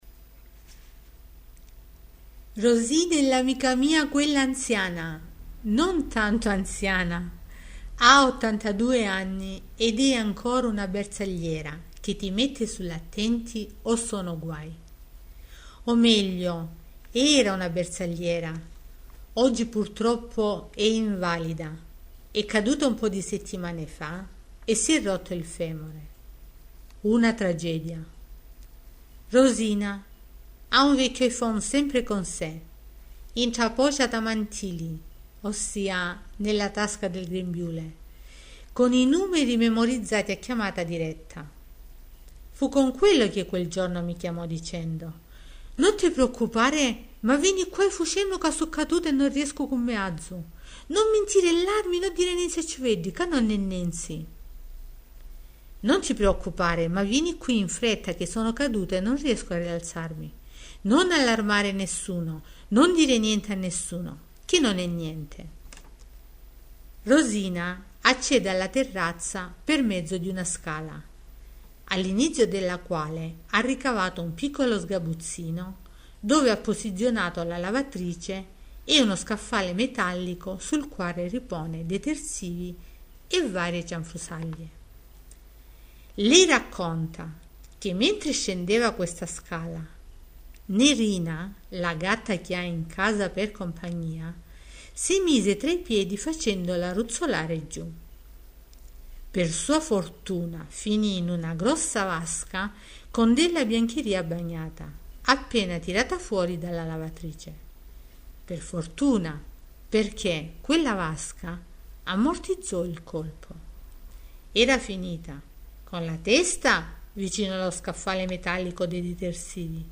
Racconto di una storia vera, in dialetto ruffanese.